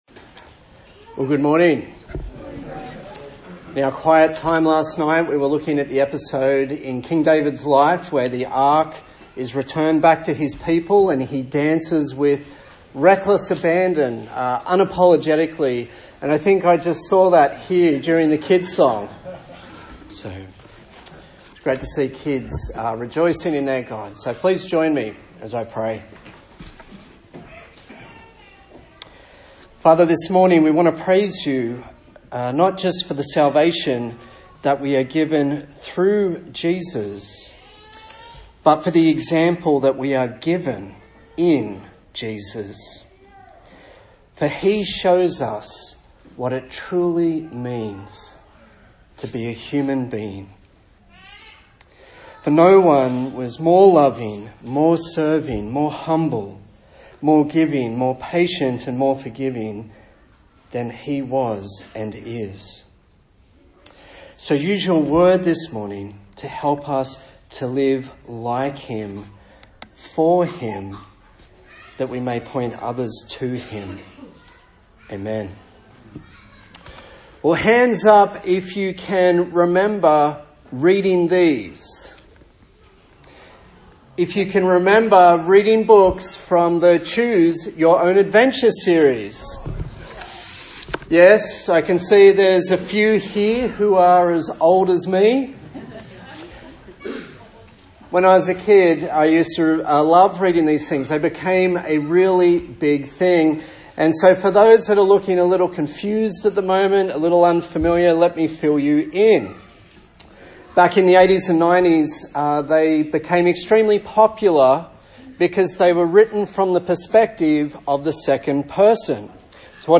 A sermon in the series on the book of Ecclesiastes
Service Type: Sunday Morning